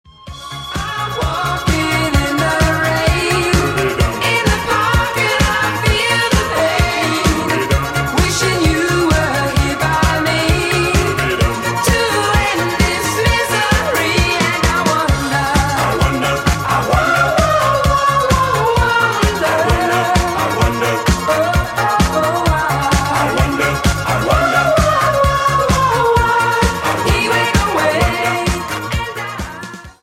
• Качество: 128, Stereo
dance
дискотека 80-х